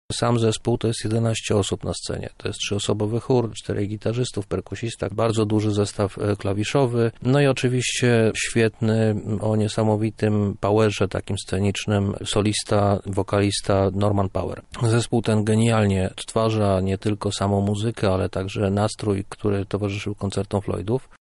Zapowiedź-Another-Pink-Floyd.mp3